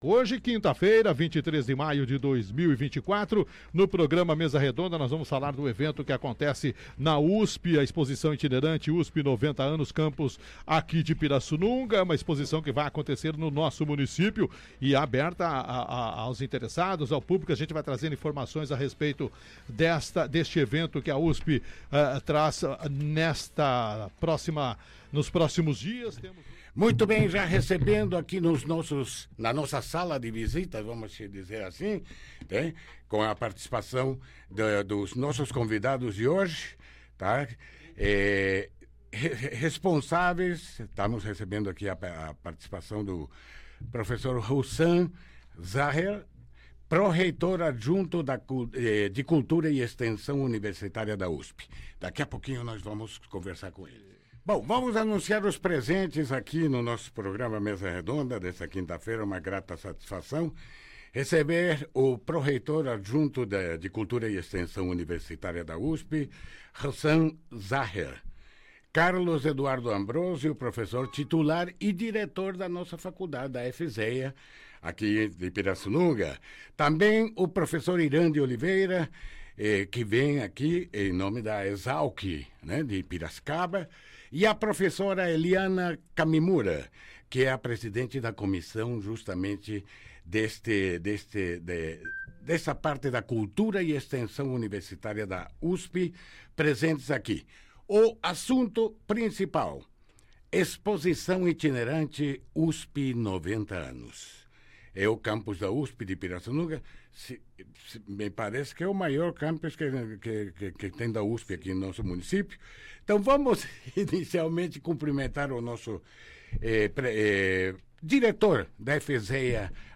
Ouça reportagem especial veiculada na Rádio USP em 11 de abril, no programa Cultura na USP :